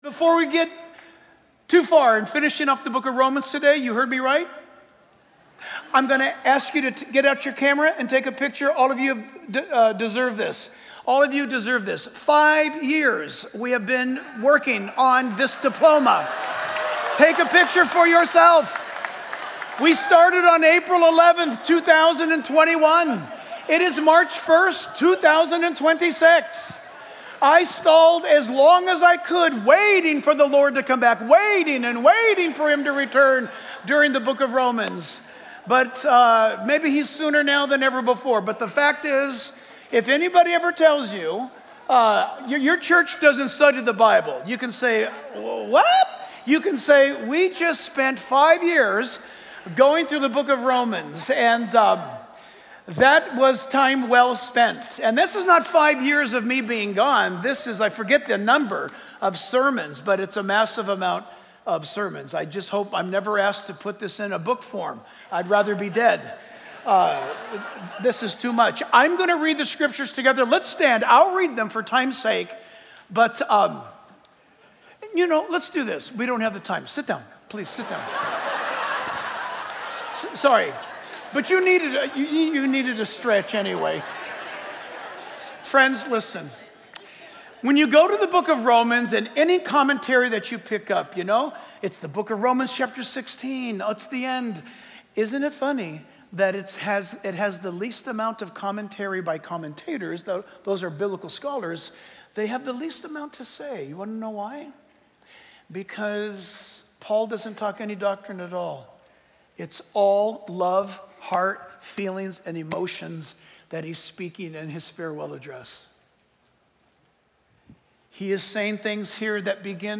Reference: Romans 15:30-33 Download Sermon MP3 Download Sermon Notes